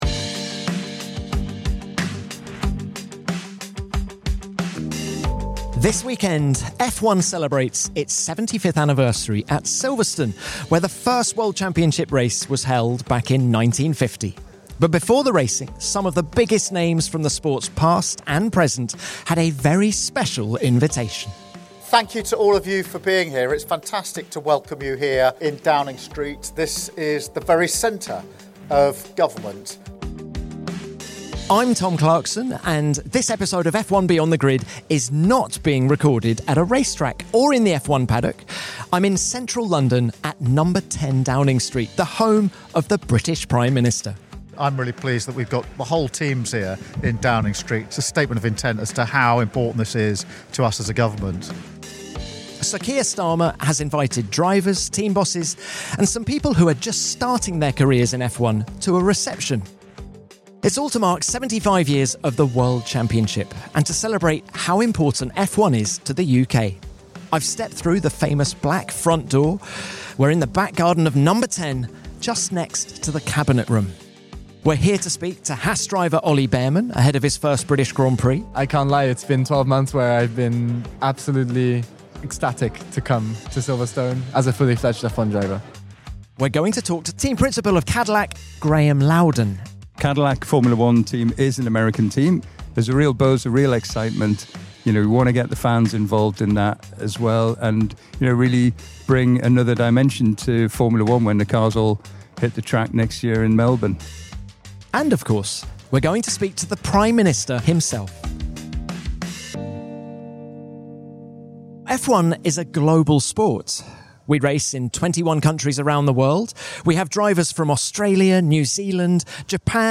Sitting in the Downing Street garden, he describes the 'surreal' feeling of stepping through Number 10's famous black front door, and looks forward to racing in front of his home fans at Silverstone.